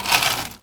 R - Foley 176.wav